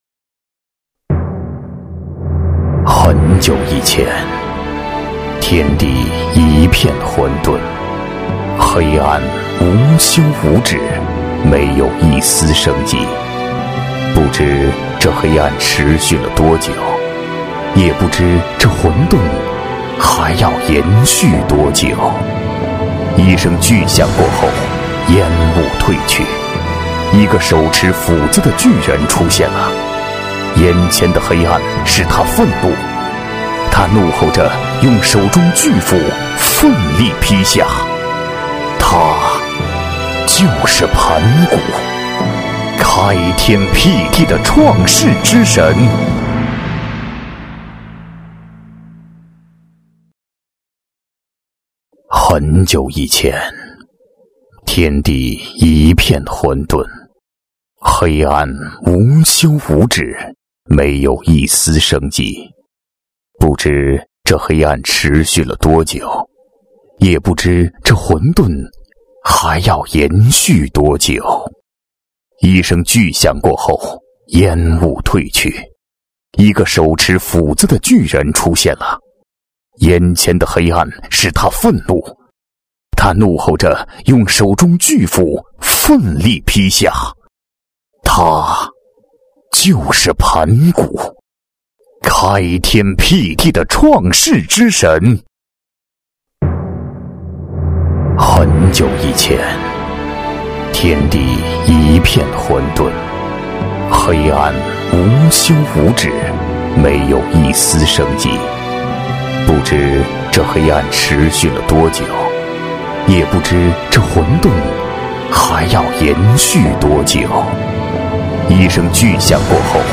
• 男S337 国语 男声 专题片-盘古-厚重、大气 大气浑厚磁性|沉稳